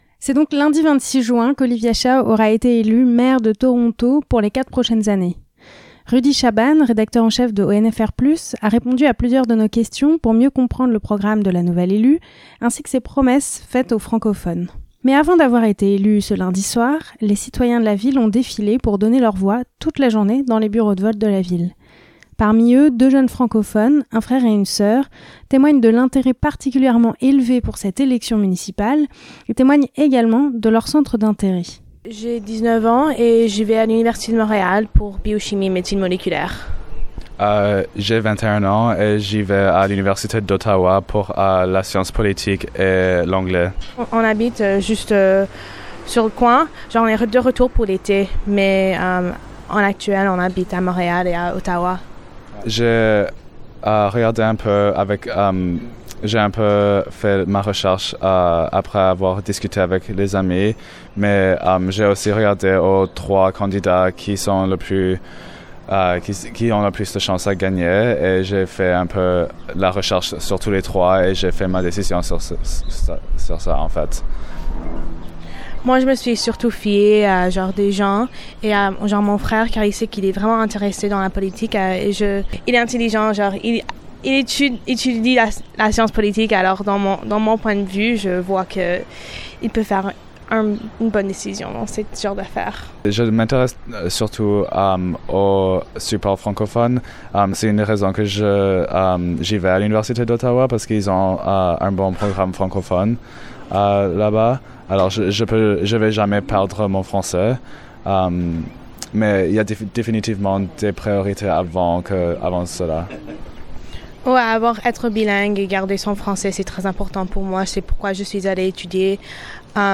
L'entrevue